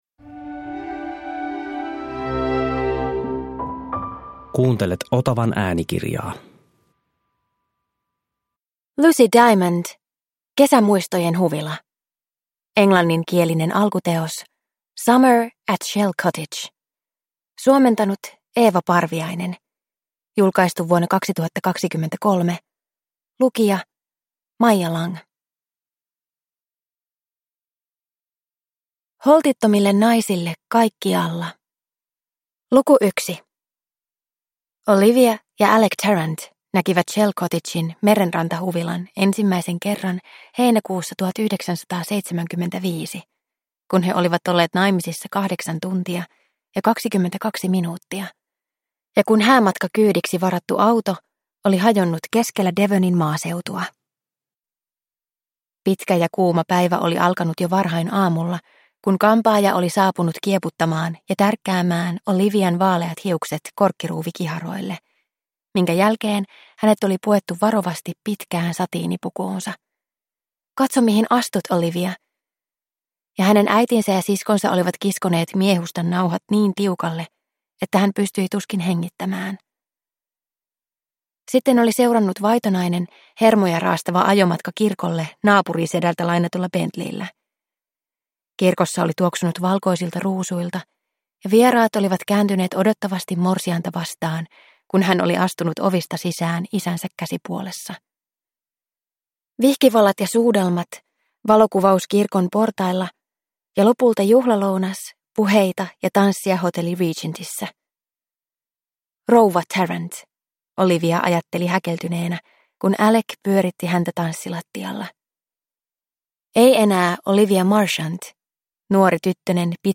Kesämuistojen huvila (ljudbok) av Lucy Diamond